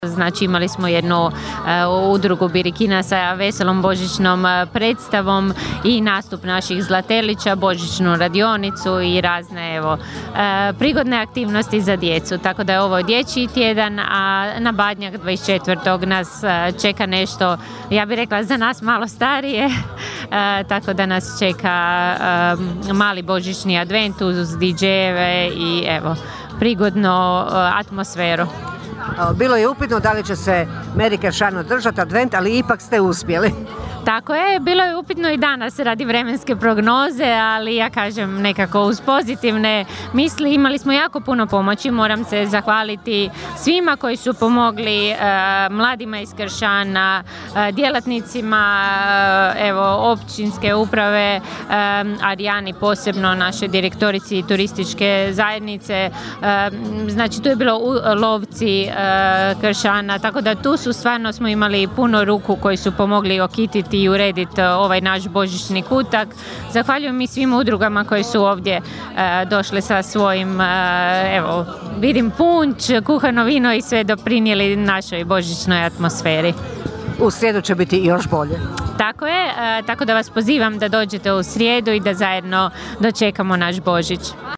Govori općinska načelnica Ana Vuksan: (